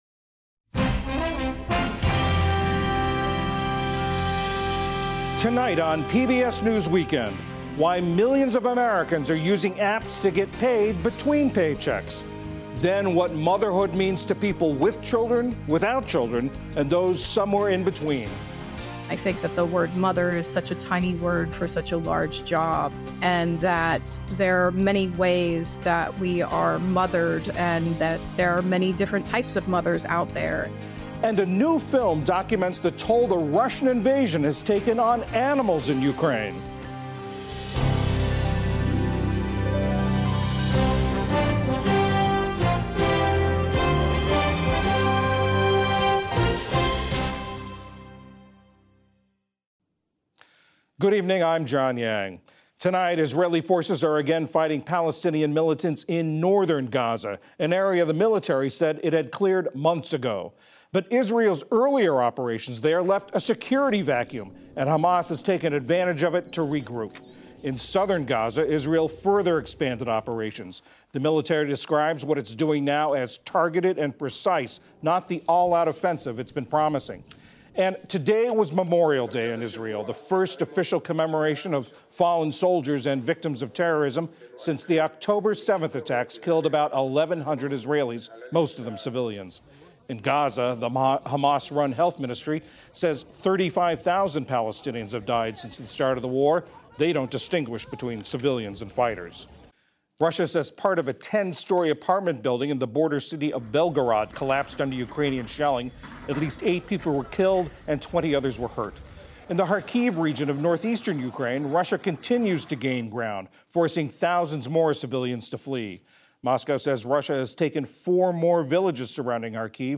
PBS NewsHour News, Daily News